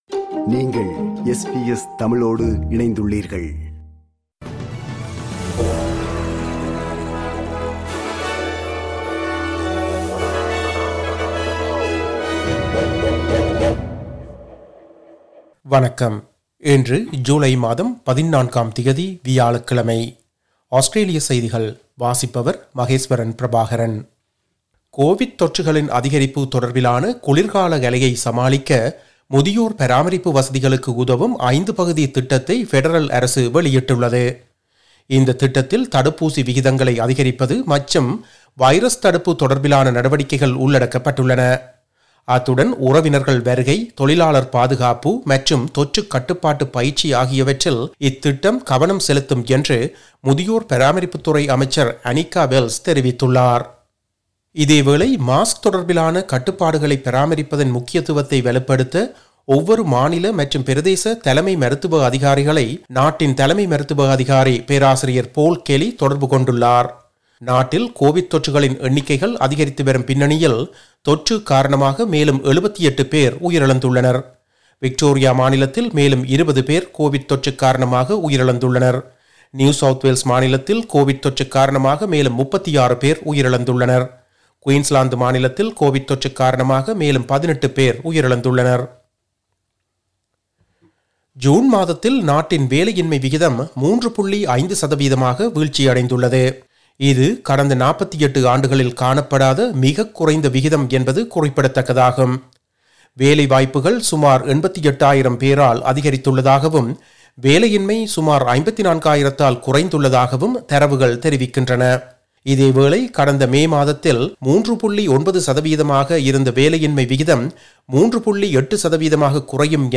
Australian news bulletin for Thursday 14 July 2022.